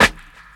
• Old School Dope Reggae Snare Single Hit A Key 213.wav
Royality free snare drum sound tuned to the A note. Loudest frequency: 1911Hz
old-school-dope-reggae-snare-single-hit-a-key-213-4nX.wav